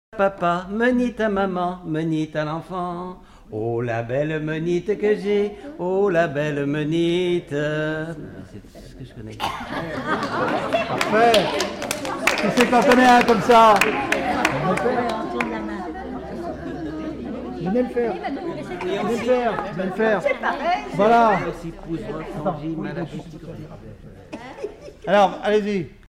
formulette enfantine : jeu des doigts
Collectif-veillée (1ère prise de son)
Pièce musicale inédite